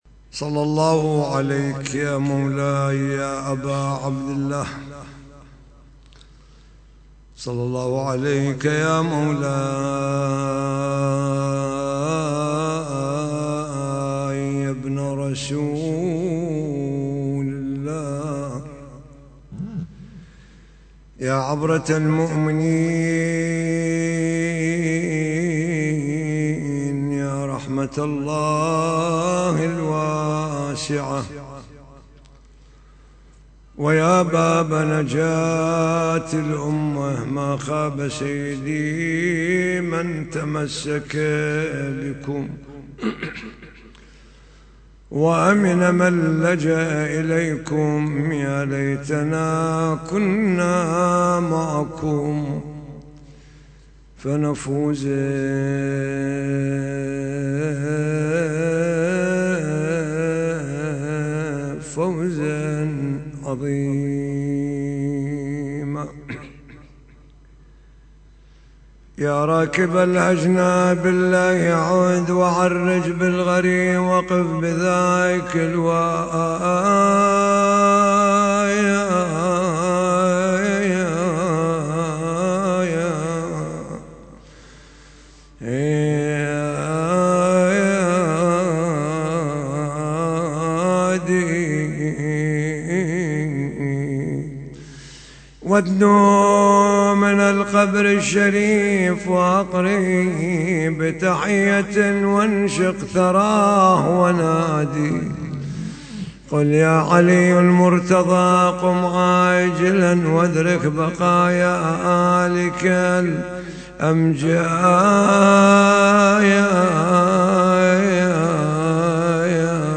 محاضرة ليلة 30 جمادى الأولى